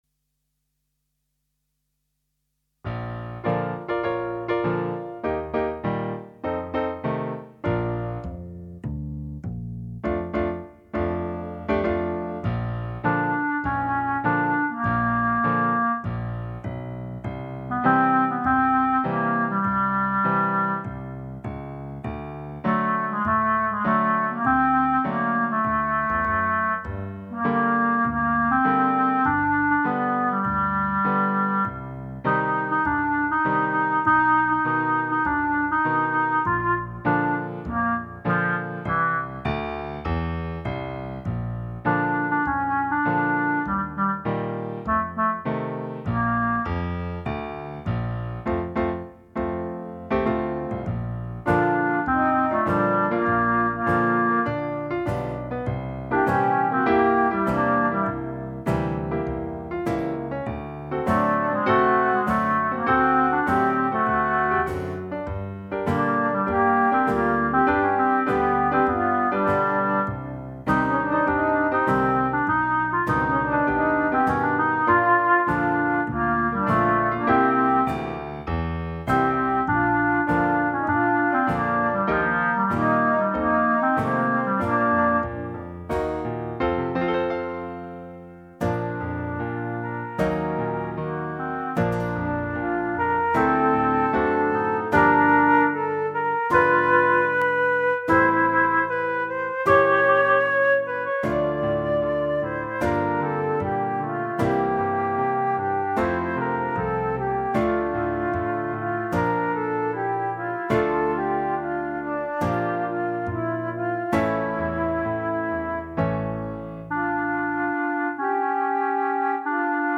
minus Drums